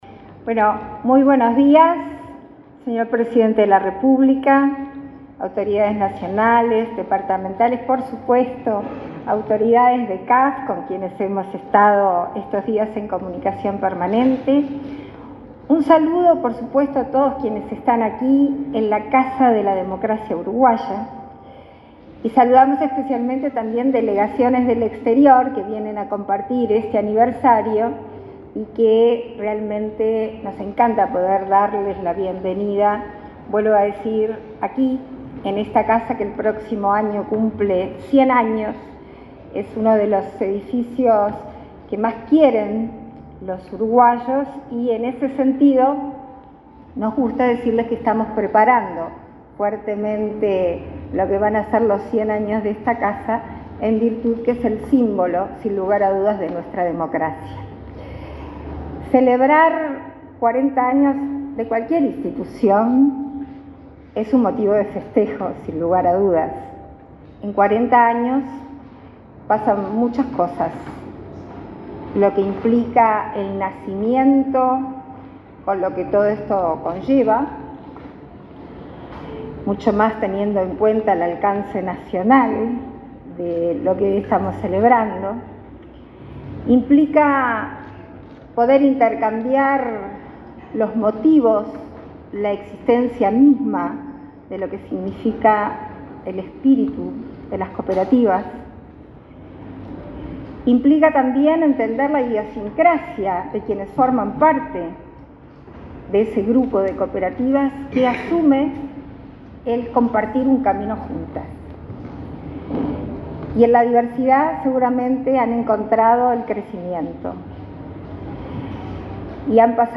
Palabras de la vicepresidenta Beatriz Argimón
Palabras de la vicepresidenta Beatriz Argimón 09/04/2024 Compartir Facebook Twitter Copiar enlace WhatsApp LinkedIn La vicepresidenta de la República, Beatriz Argimón, participó, este martes 9 en el Palacio Legislativo, en la celebración del 40.° aniversario de las Cooperativas Agrarias Federadas.